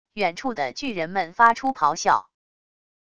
远处的巨人们发出咆哮wav音频